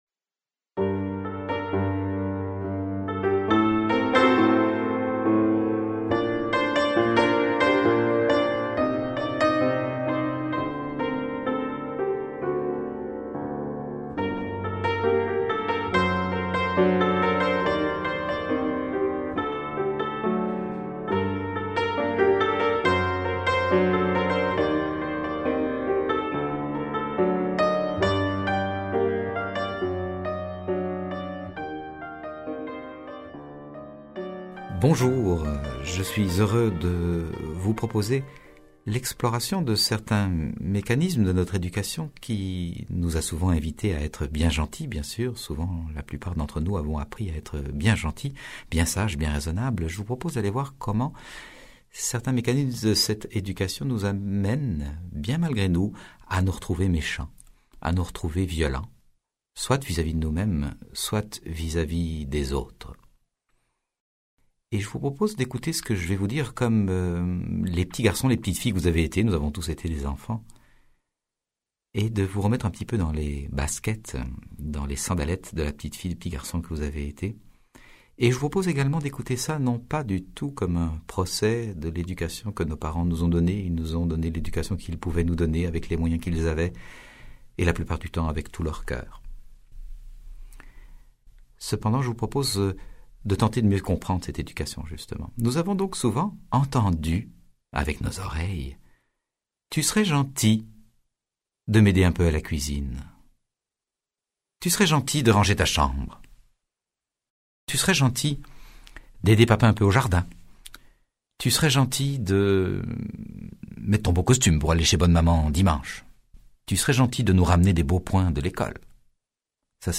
Ce livre audio est une invitation à désamorcer la mécanique de la violence, là où elle s’enclenche toujours: dans la conscience et le cœur de chacun de nous.